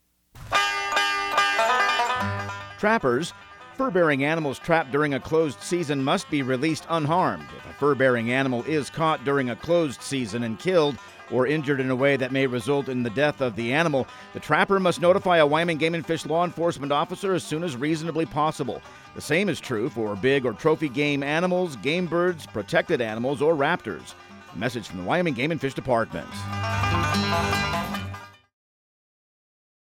Outdoor Tip/PSA